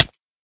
hit2.ogg